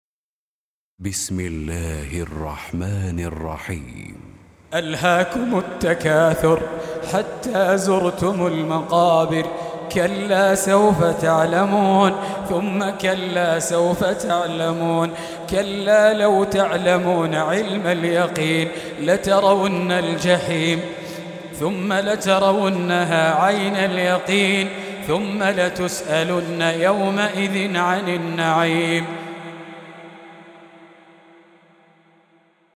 102. Surah At-Tak�thur سورة التكاثر Audio Quran Tarteel Recitation
Surah Repeating تكرار السورة Download Surah حمّل السورة Reciting Murattalah Audio for 102.